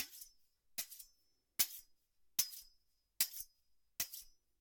Afilando un cuchillo
Cocina - Zona de preelaboración
Sonidos: Acciones humanas